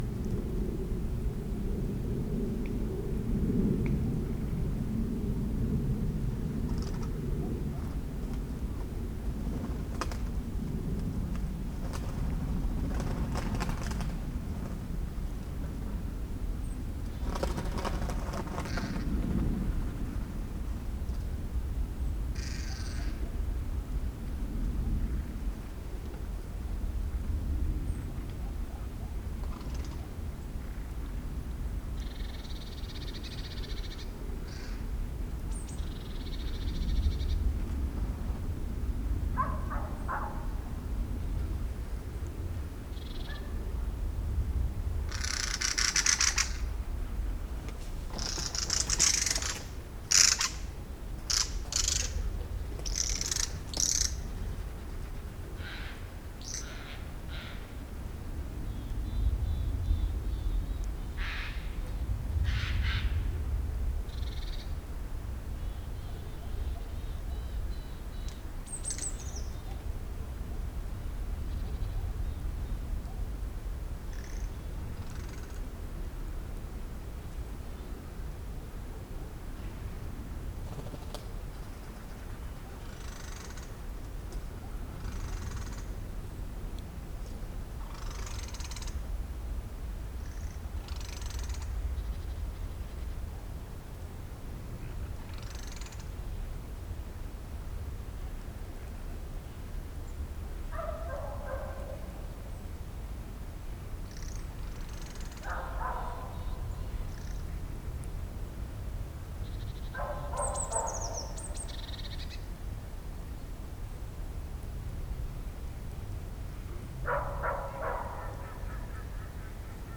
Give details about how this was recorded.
PFR07477, 130210, river Mulde soundscape, Mörtitz, Germany